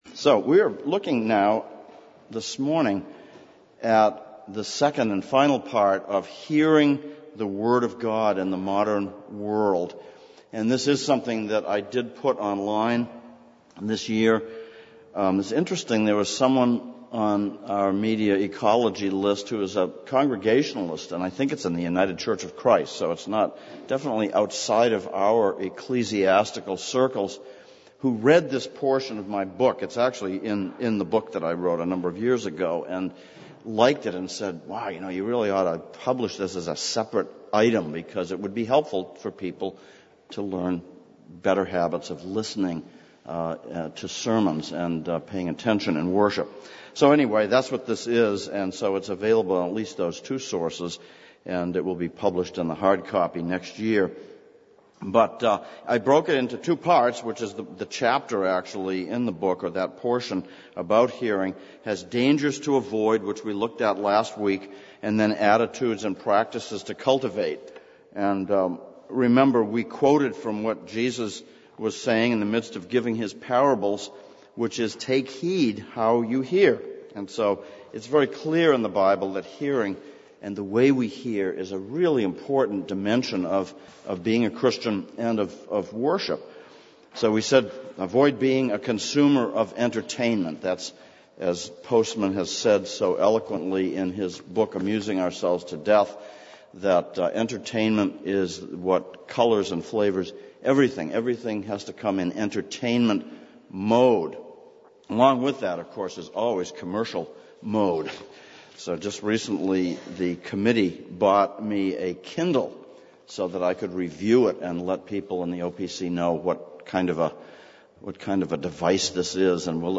Series: Hearing the Word in the Modern World Service Type: Adult Sunday School